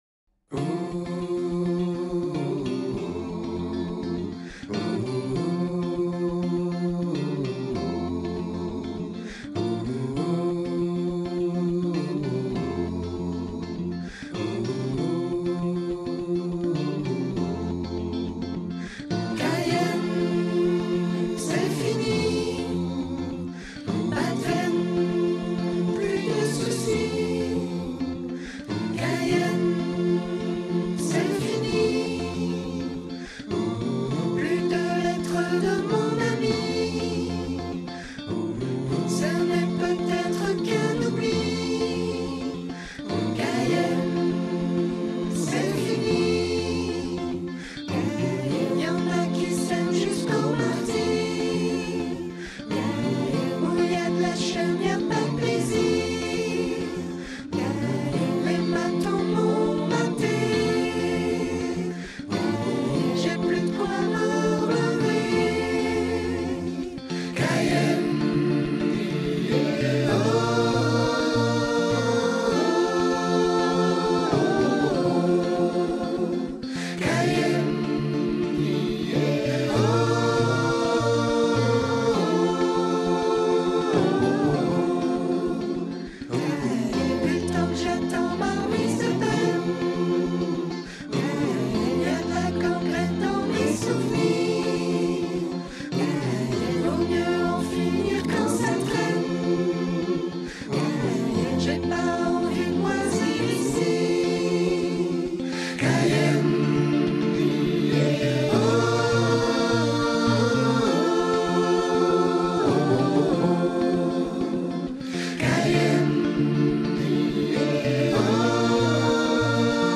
Modèle d'Ensemble satb